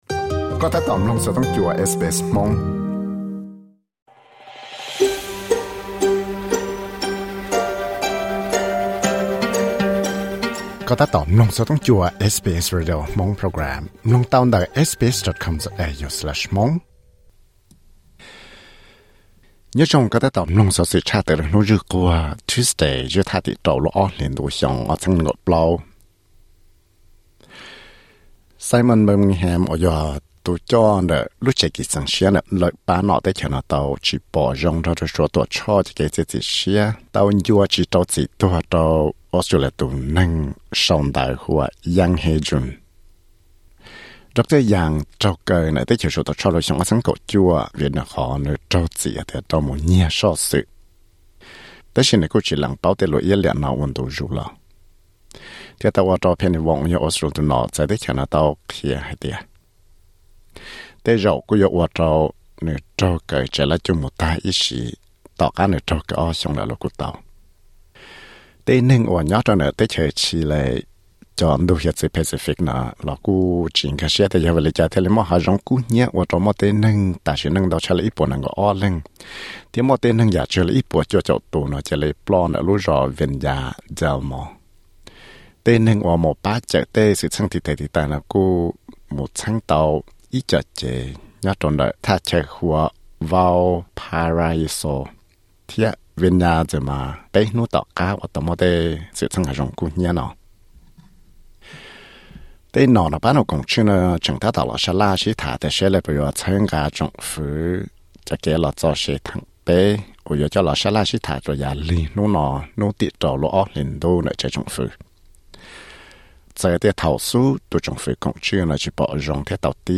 Xov xwm luv tshaj tawm hnub zwj Quag (Tuesday newsflash 06.02.2024).